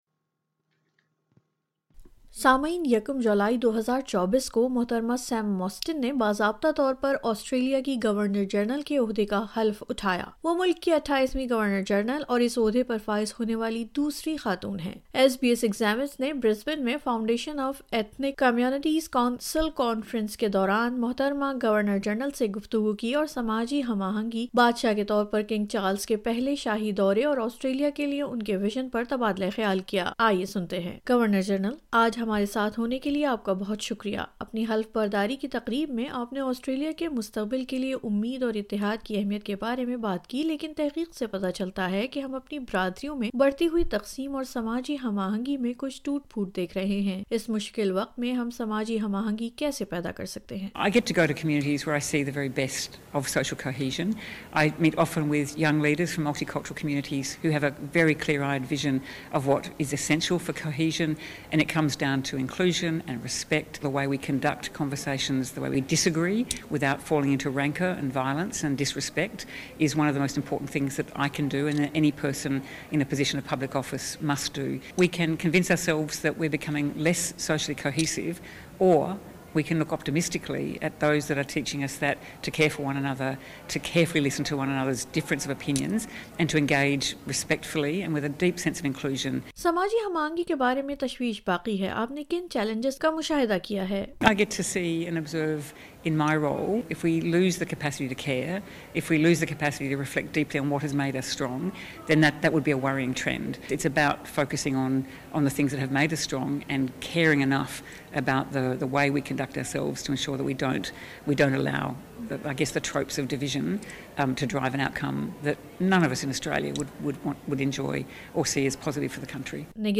ایس بی ایس ایگزامنزکے ساتھ ایک انٹرویو میں عزت مآب سیم موسٹین نے سماجی ہم آہنگی، پرامیدی اور بادشاہت کے کردار کے بارے میں اپنے خیالات کا اظہار کیا ہے ۔